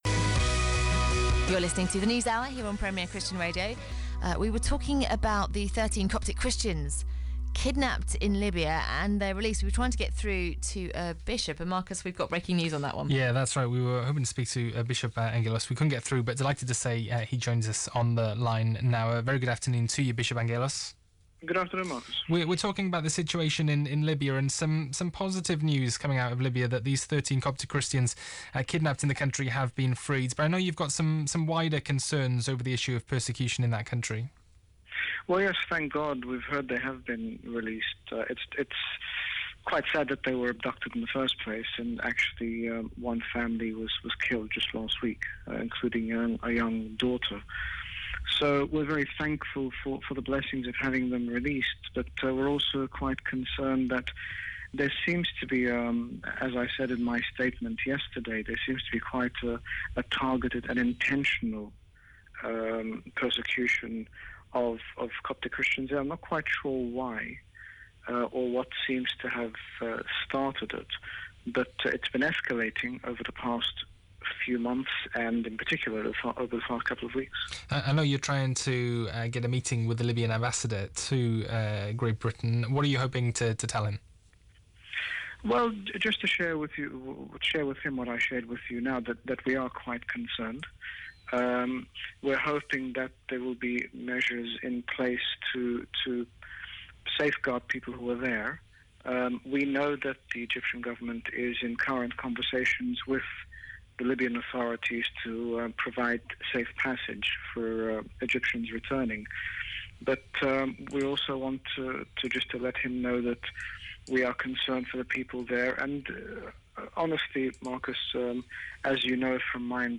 Radio interview with HG Bishop Angaelos re Libya
Premier Christian Radio interviews His Grace Bishop Angaelos, General Bishop of the Coptic Orthodox Church about the escalating attacks against Coptic Christians in Libya.
Premier Interview re Libya.mp3